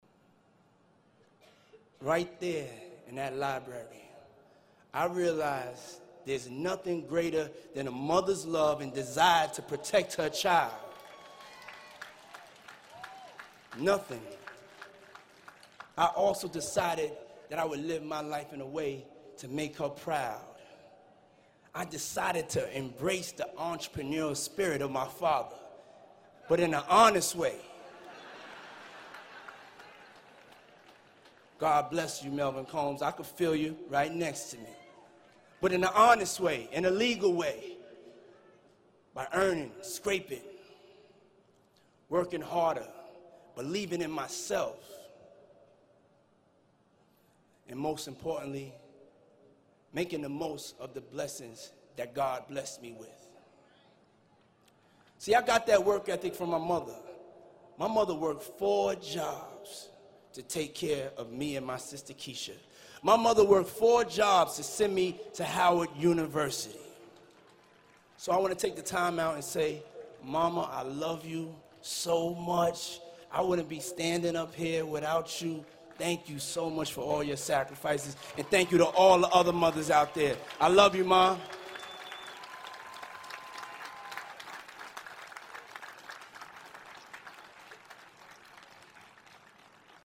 公众人物毕业演讲 第50期:肖恩库姆斯霍华德大学(6) 听力文件下载—在线英语听力室